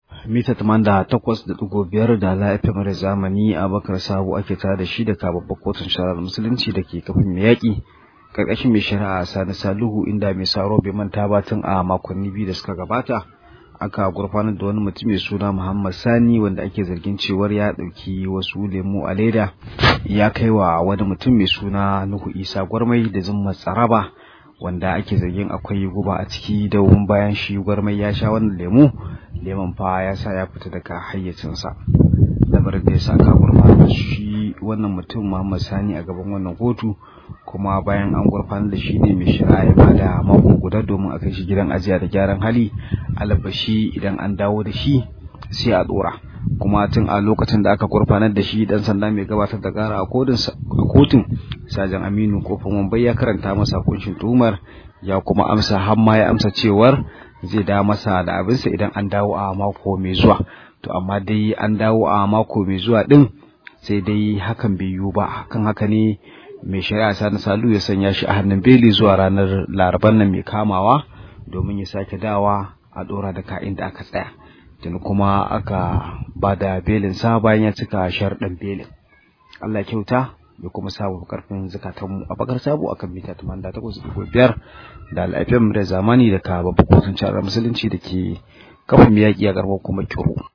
Rahoto: An bayar da belin wanda ake zargi da shafi mulera